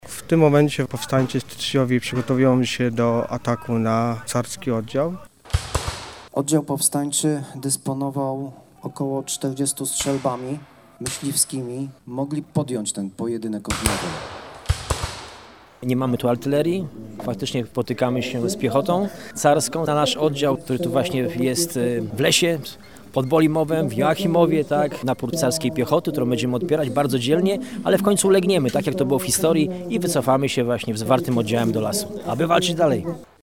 W Joachimowie-Mogiłach pod Bolimowem odbyła się inscenizacja historyczna upamiętniająca 161. rocznicę wybuchu powstania styczniowego.
Potem uczestnicy z różnych grup rekonstrukcyjnych z całej Polski przedstawili potyczkę wojsk rosyjskich z powstańczym oddziałem Władysława Strojnowskiego.
inscenizacja-potyczki-powstania-styczniowego.mp3